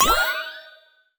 magic_deflect_spell_impact2.wav